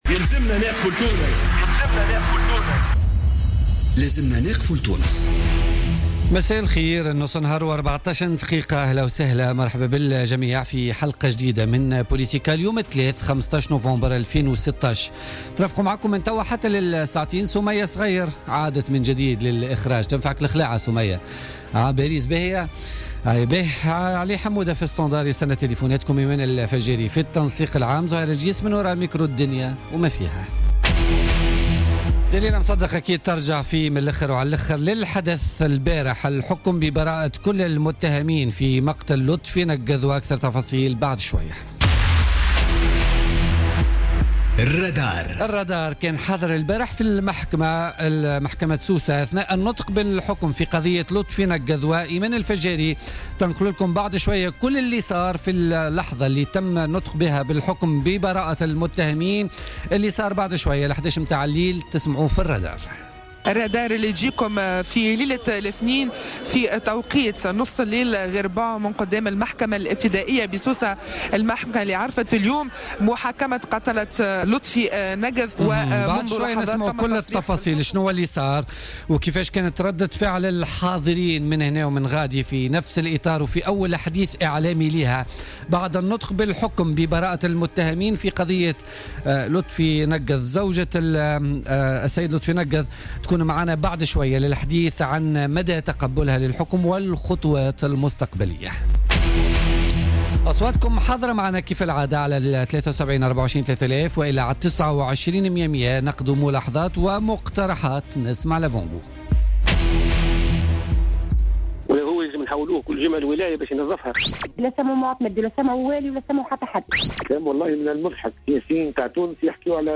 Interview exclusive